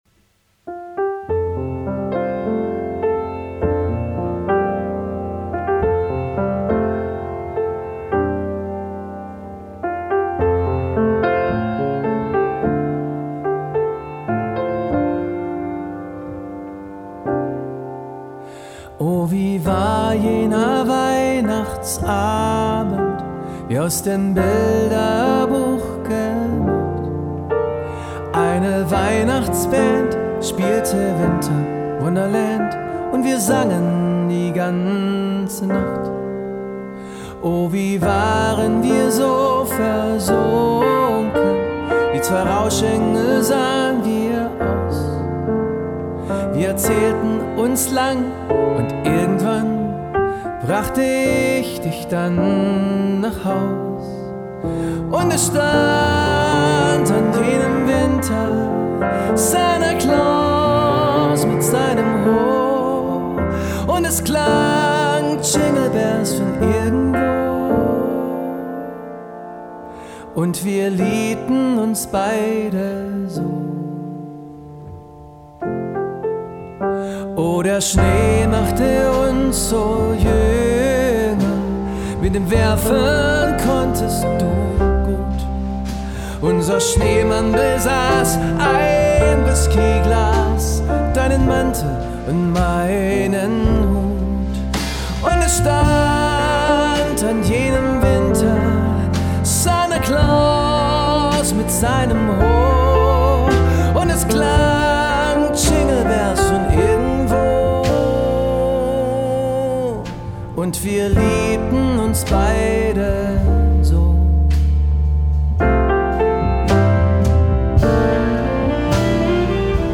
Сводил на JBL LSR305.